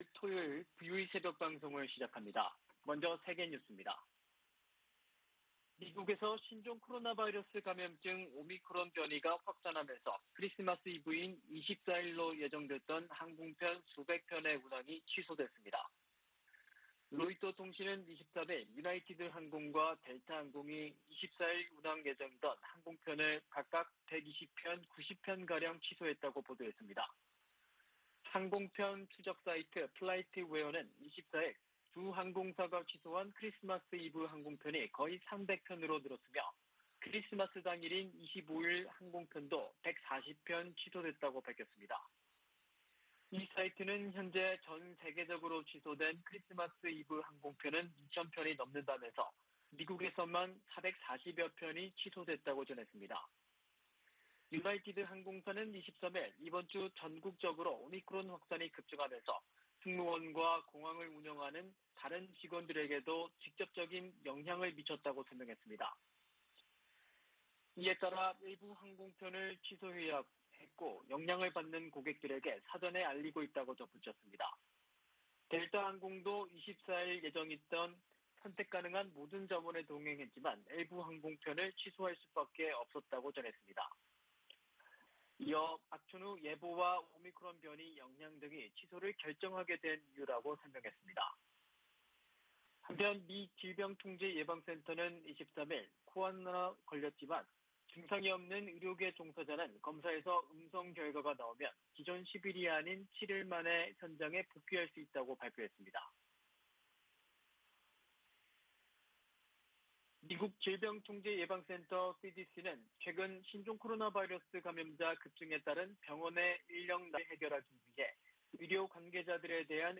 VOA 한국어 '출발 뉴스 쇼', 2021년 12월 25일 방송입니다. 미국 내 구호단체들은 올 한 해가 대북 지원 사업에 가장 도전적인 해였다고 밝혔습니다. 미 델라웨어 소재 ‘TD 뱅크그룹’이 대북 제재 위반 혐의로 11만5천 달러 벌금에 합의했다고 미 재무부가 밝혔습니다. 한국과 중국이 4년 반 만에 가진 외교차관 전략대화에서 종전선언 등에 협력 방안을 논의했습니다.